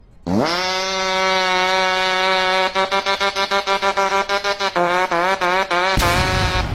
Fart Sound Button